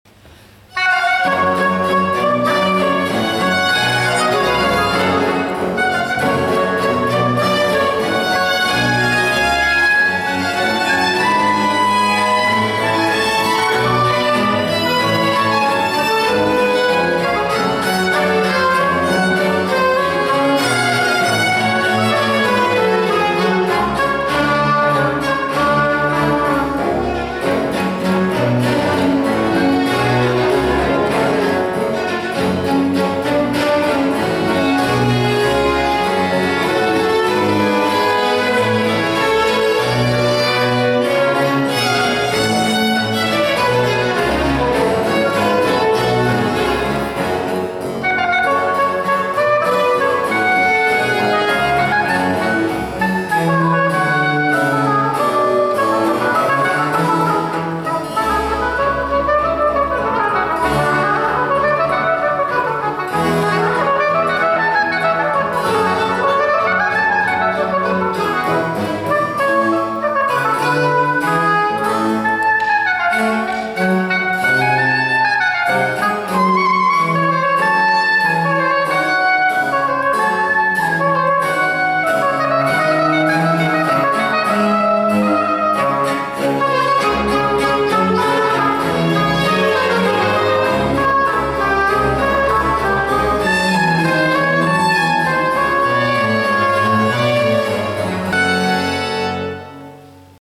G.B. Platti, concert voor hobo, strijkers en continuo in g kl.
Baroque hobo